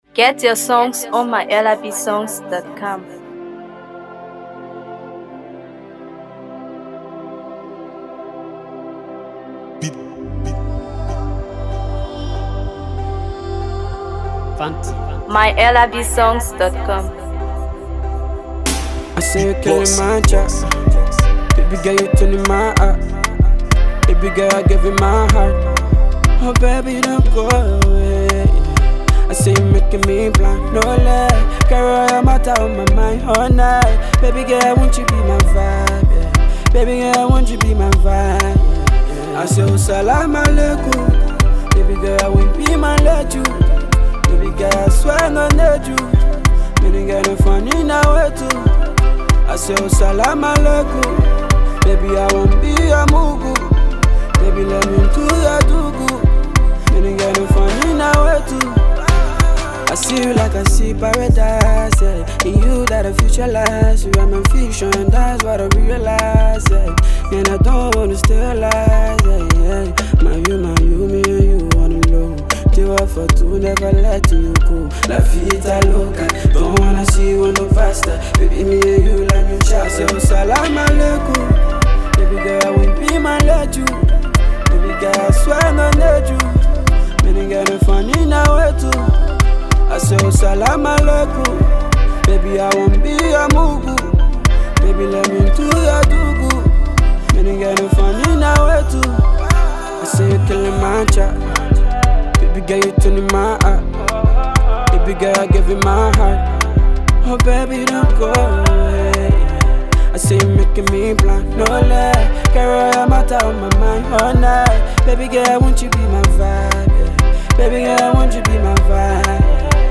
infectious melodies